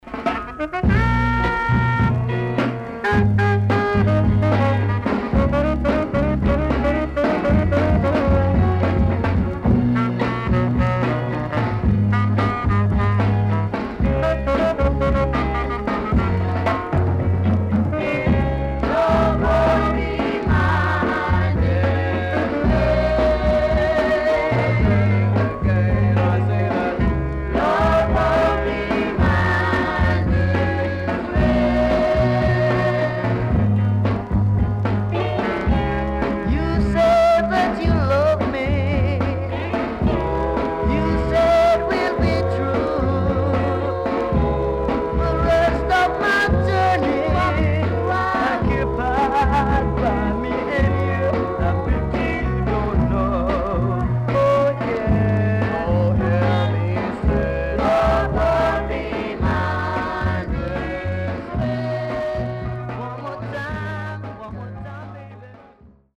SKA
SIDE B:所々チリノイズがあり、少しプチノイズ入ります。